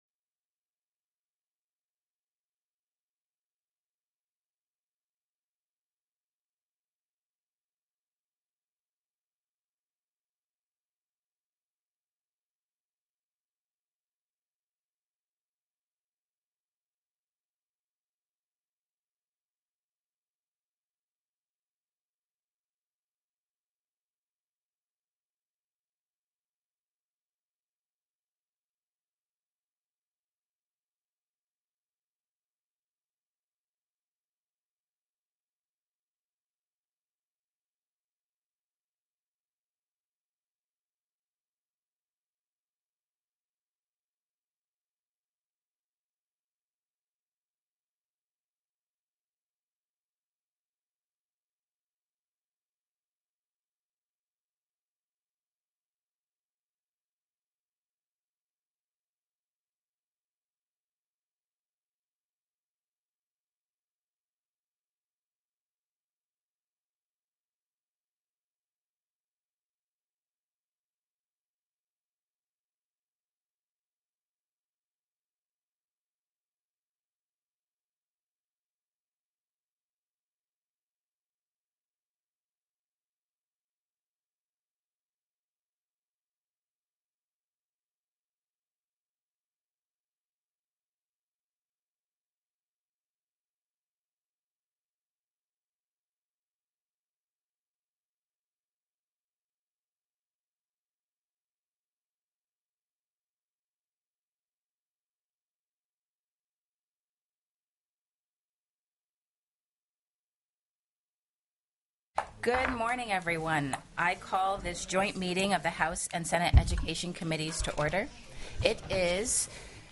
The audio recordings are captured by our records offices as the official record of the meeting and will have more accurate timestamps.
+ Meeting Jointly with House Education Committee TELECONFERENCED